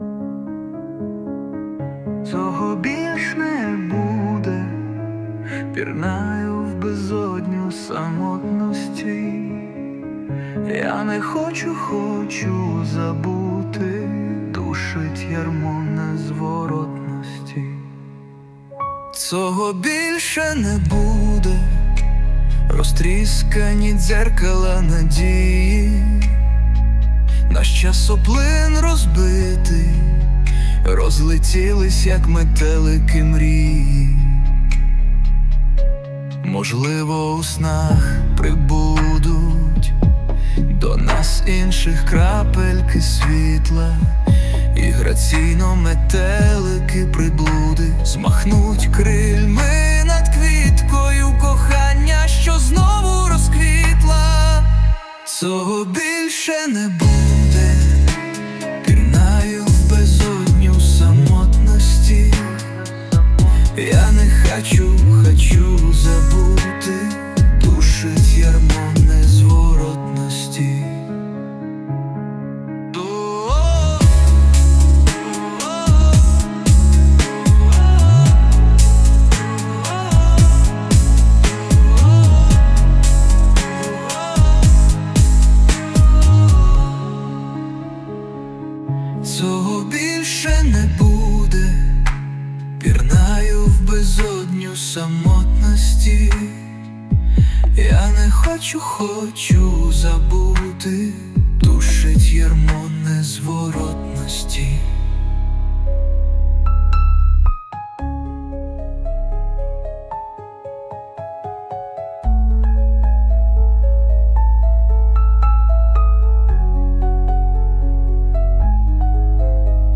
(Вірш мій. Заспівав ШІ)